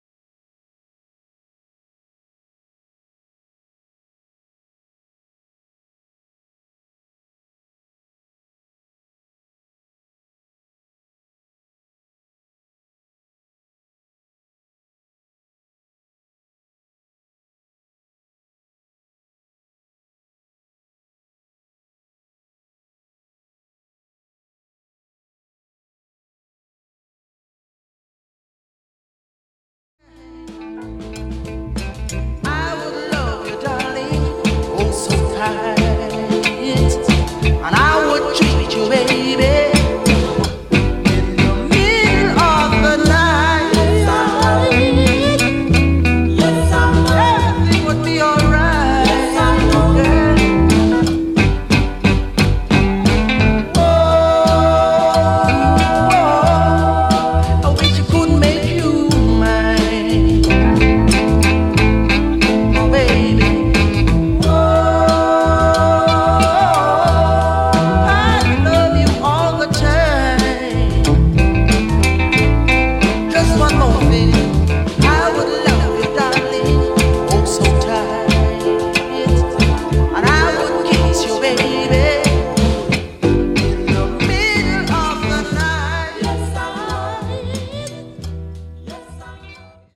Soul infused reggae two tracker